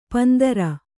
♪ pandara